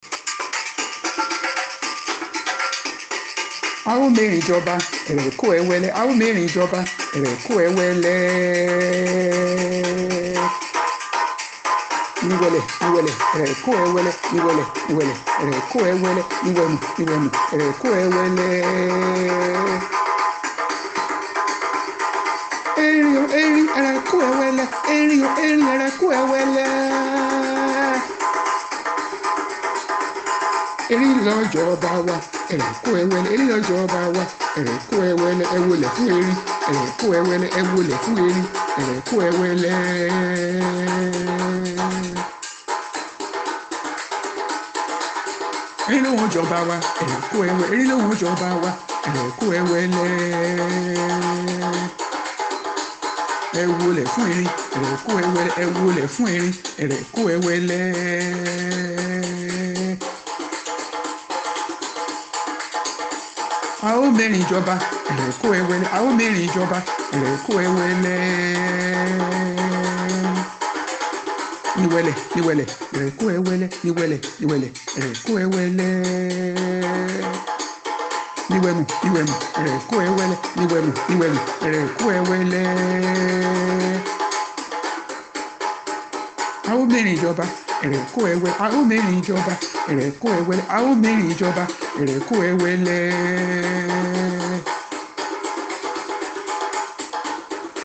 Very soon the following song, could be heard from the combination of musical instruments and voices: